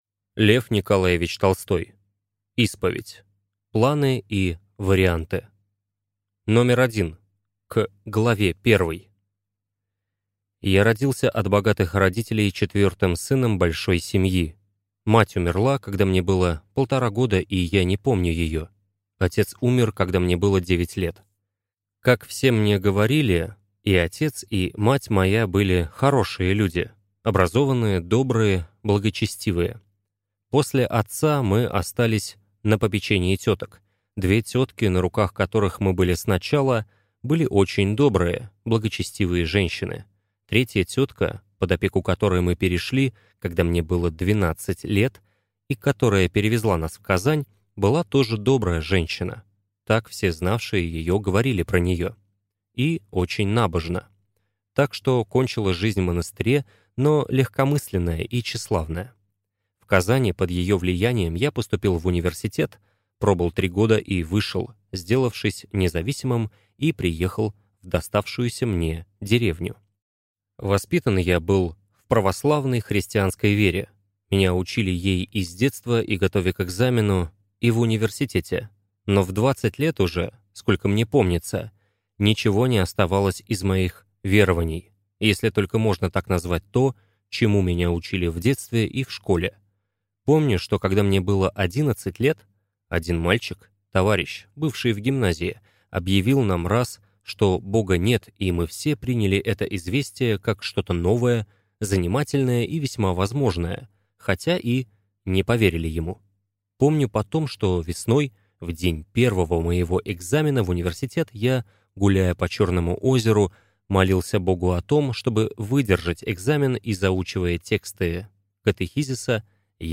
Аудиокнига Исповедь (Планы и варианты) | Библиотека аудиокниг